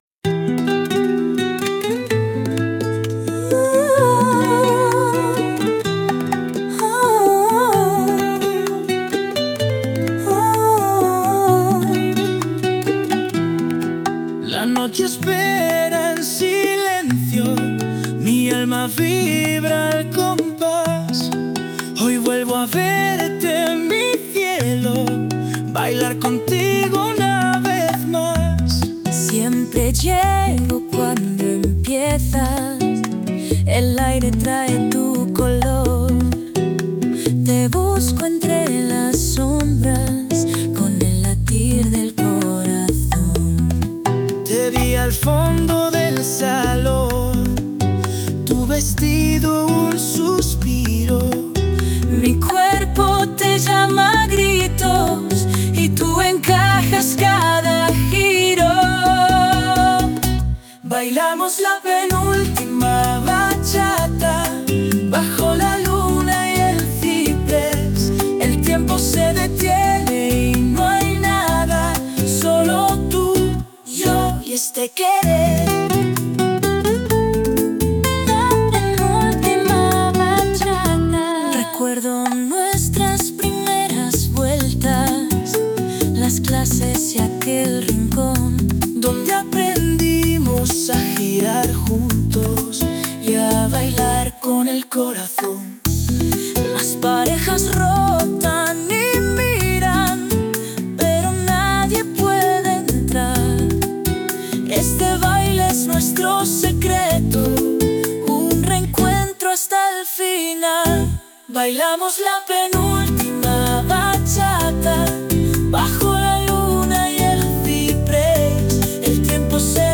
Por cierto, si más arriba os ponía una canción instrumental con la que acompañar la lectura de esta obra, aquí tenéis una versión cantada. La música es diferente a la que anteriormente habéis escuchado y la letra y voces… Uffff…